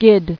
[gid]